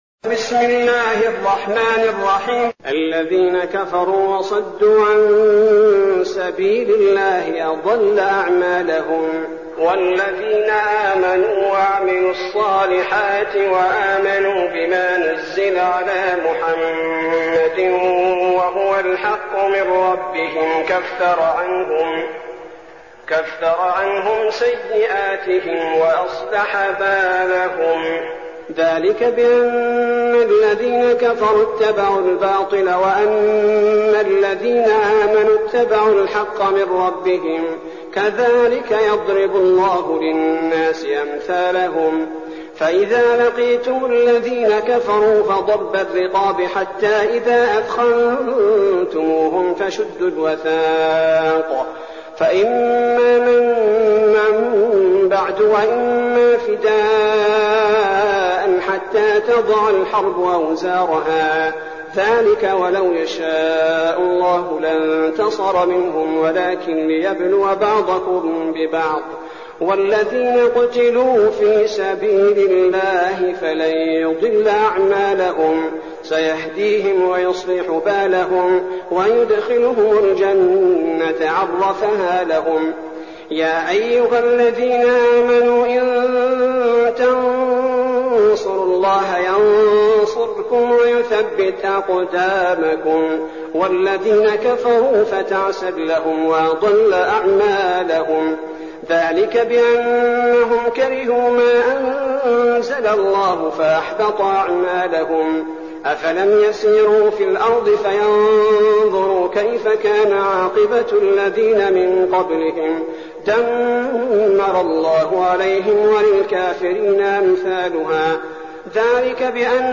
المكان: المسجد النبوي الشيخ: فضيلة الشيخ عبدالباري الثبيتي فضيلة الشيخ عبدالباري الثبيتي محمد